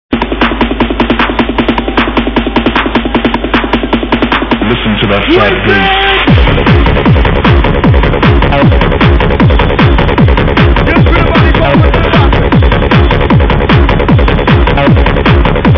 sounds like acid to me